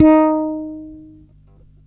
41-D#4.wav